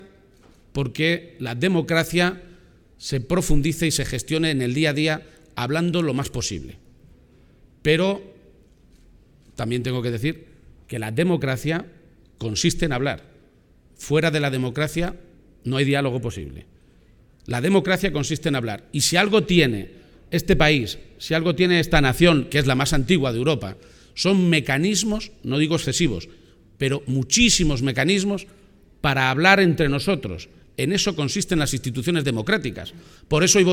en el marco de la inauguración de FARCAMA